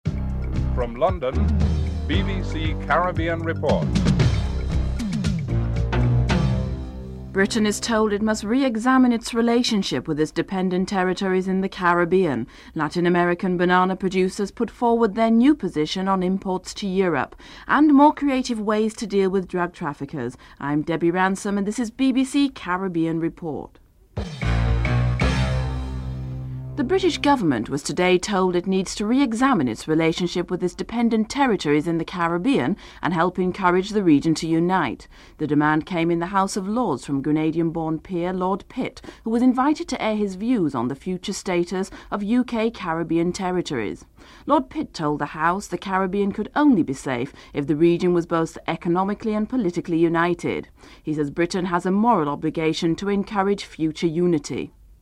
1. Headlines (00:00-00:27)
9. Theme music (15:02-15:16)